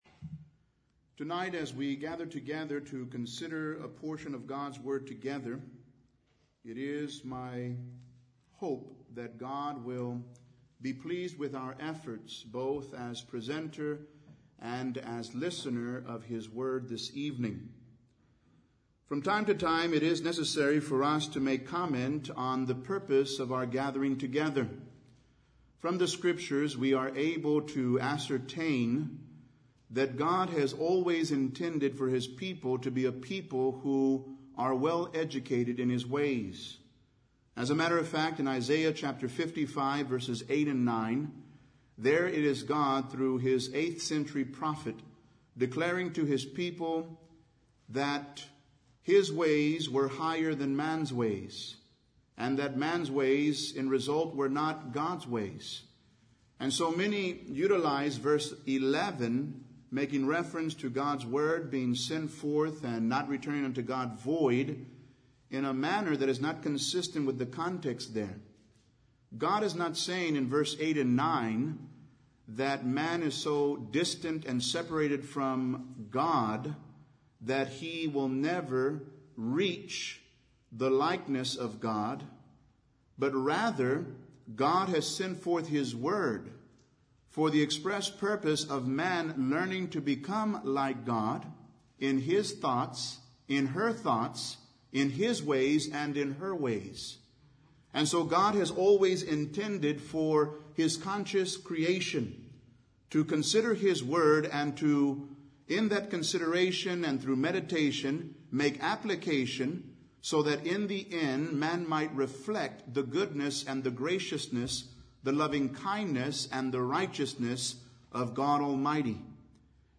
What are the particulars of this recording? Event: 26th Annual Shenandoah Lectures